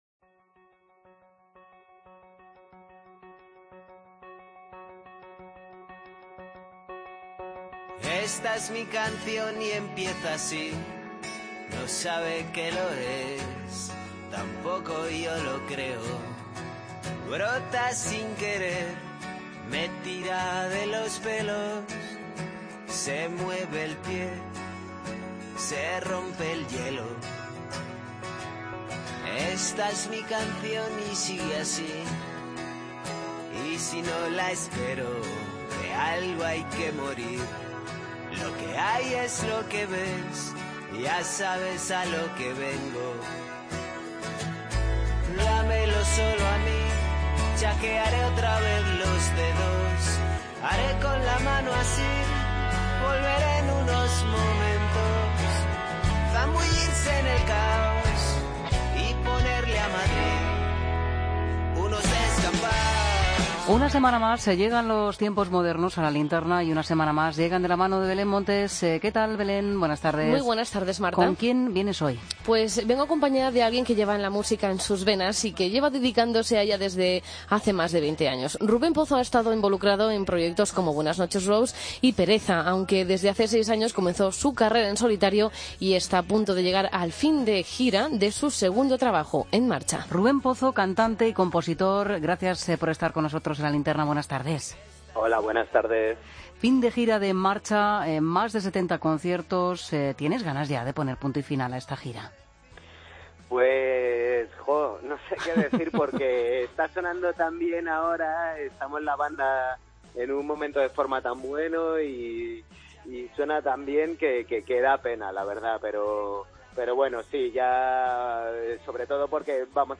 Entrevista a Rubén Pozo en La Linterna, miércoles 1 de marzo de 2017